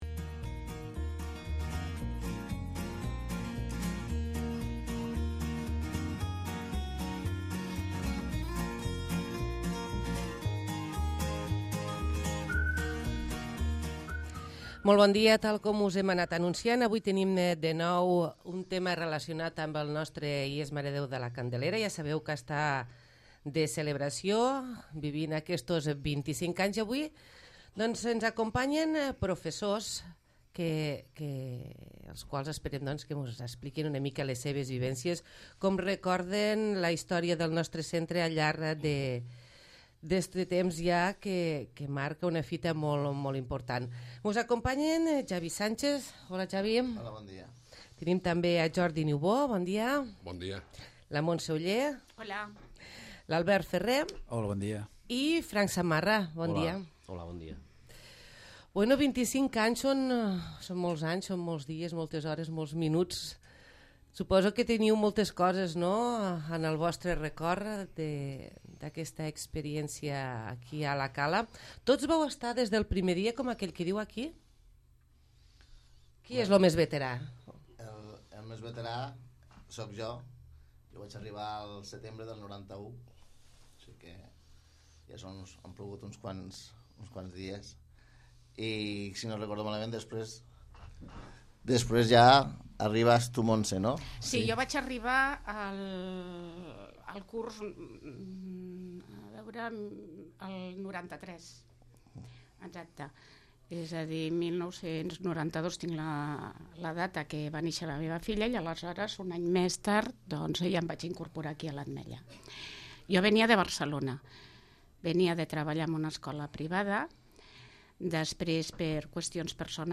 Tertúlia Institut